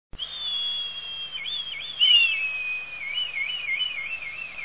Kania ruda - Milvus milvus
głosy